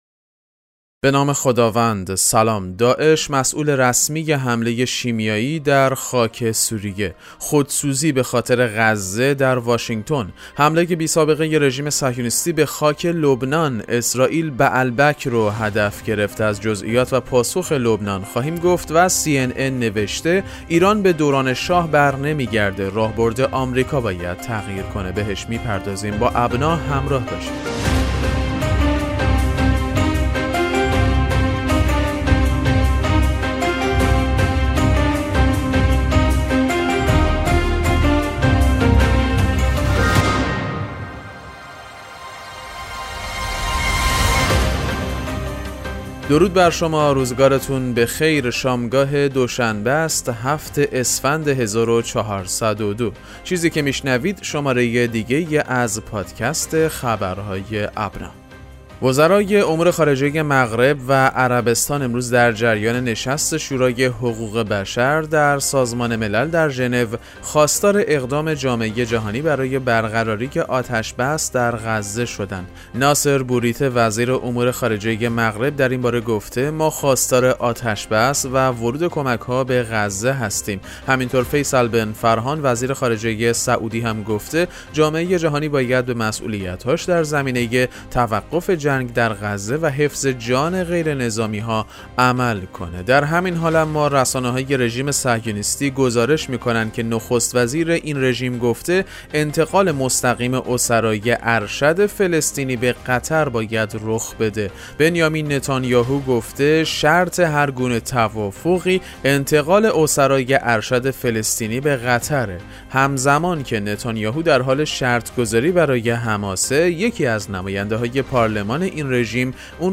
پادکست مهم‌ترین اخبار ابنا فارسی ــ 7 اسفند 1402